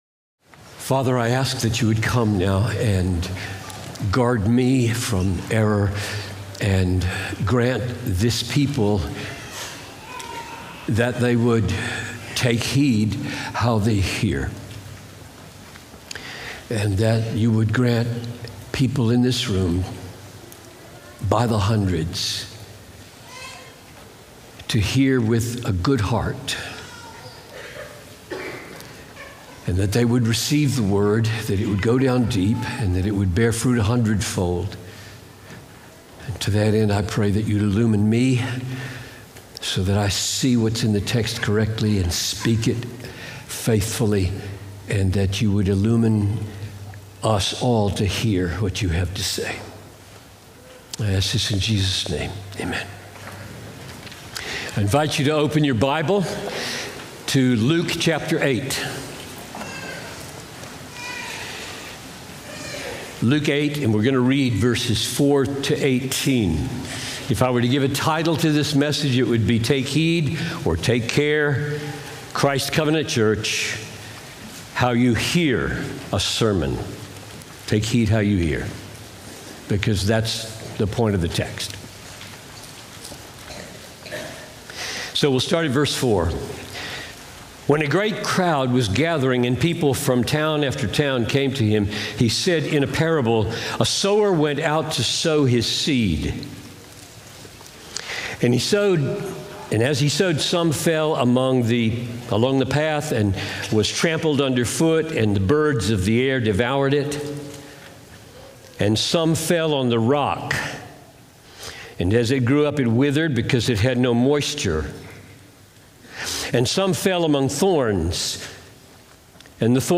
Christ Covenant Church | Matthews, NC Message by John Piper